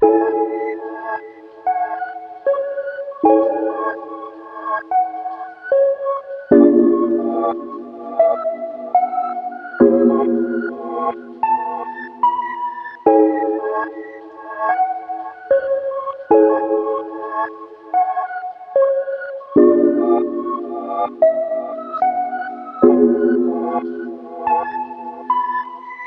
Moody Prophet Progression.wav